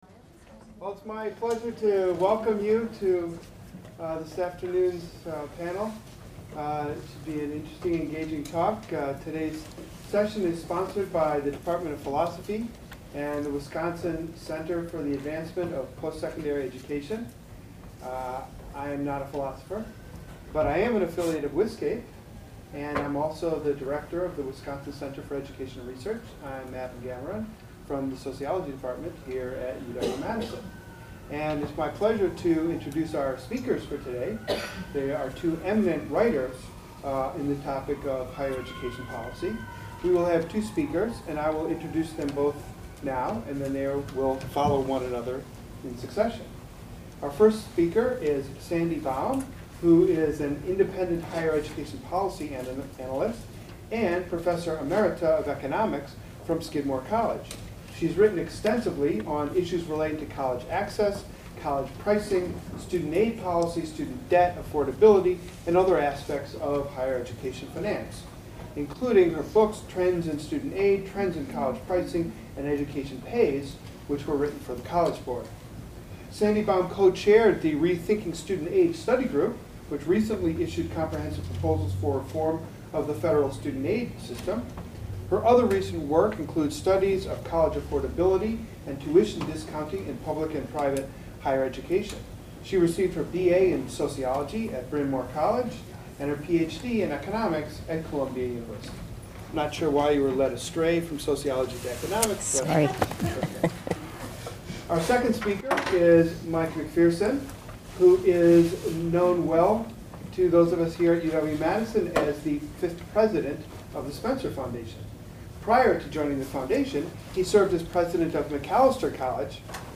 This effort to open the classroom to the campus community and the public broadly is co-sponsored by the Department of Philosophy.